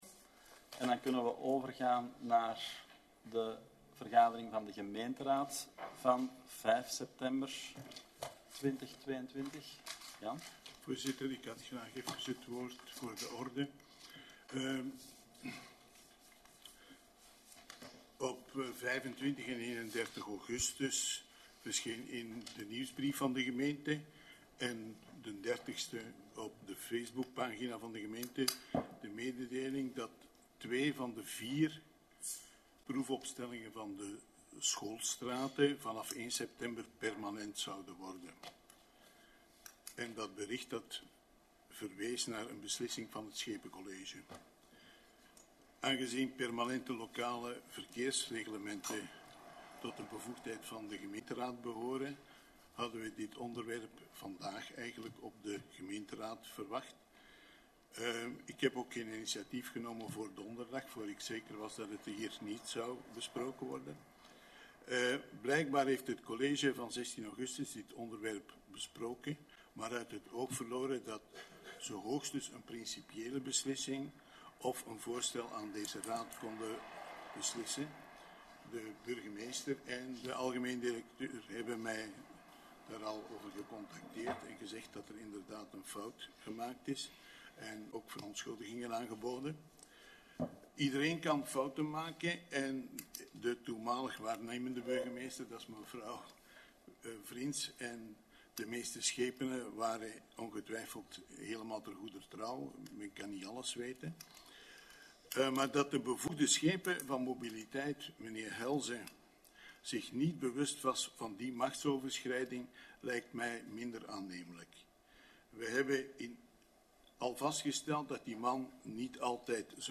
Gemeenteraad 5 september 2022